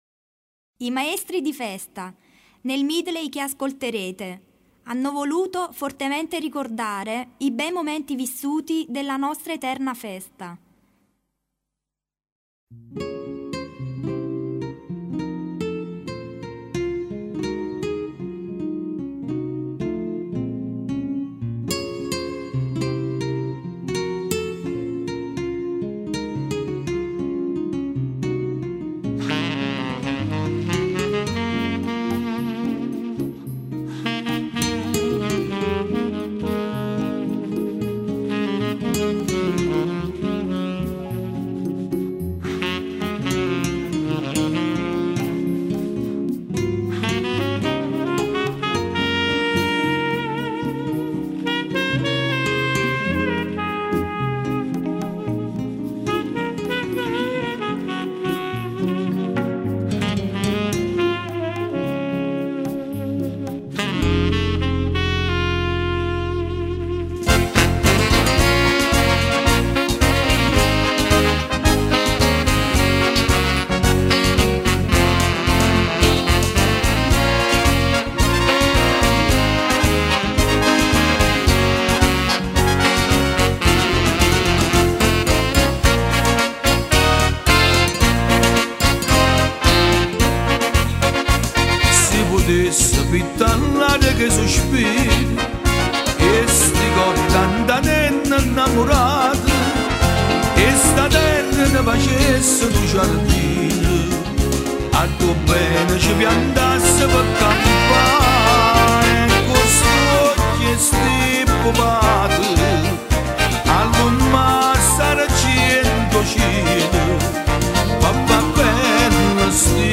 Canzoni D'Occasione Giglio del Salumiere 2005 paranza Nolana Stella
Medley-1.mp3